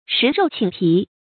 食肉寢皮 注音： ㄕㄧˊ ㄖㄡˋ ㄑㄧㄣˇ ㄆㄧˊ 讀音讀法： 意思解釋： 吃他們的肉；剝下他們的皮當褥子墊。